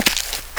fsGrass4.WAV